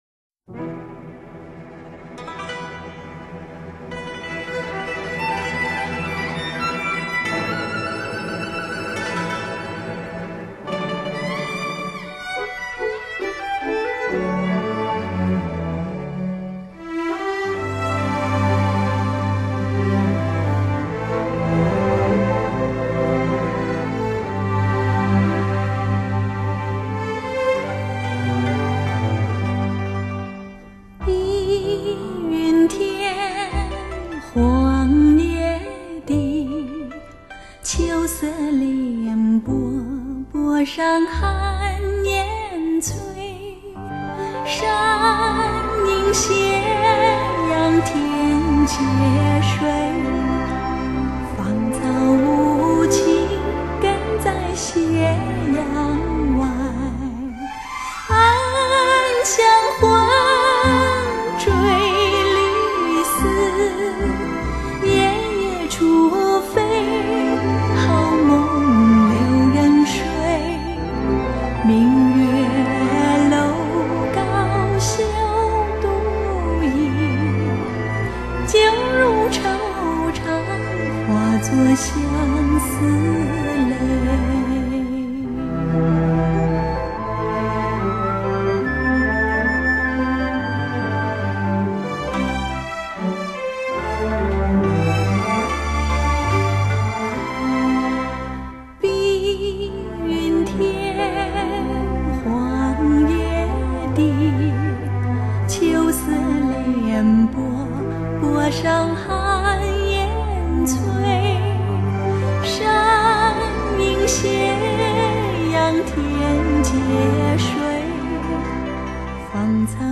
该专辑以原版录音再经过最新技术作后期处理，声音宽阔清澈。